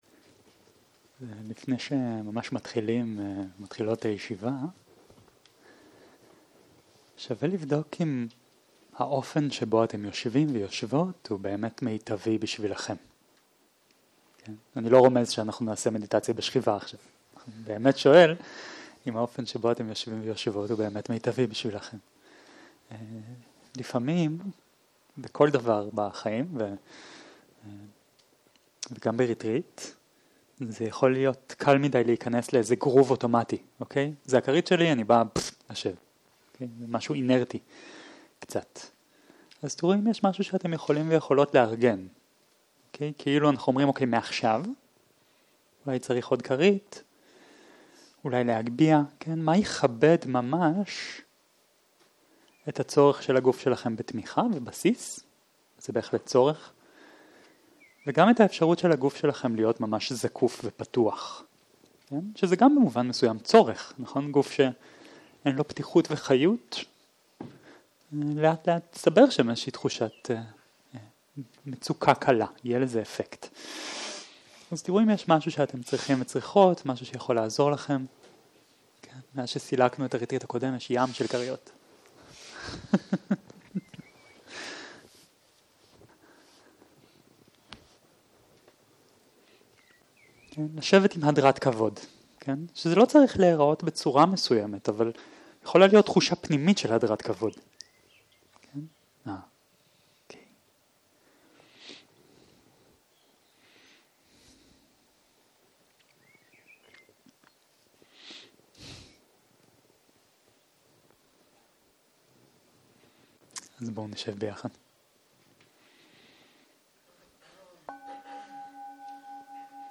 15.02.2023 - יום 8 - צהרים - מדיטציה מונחית - אנאטה - הקלטה 11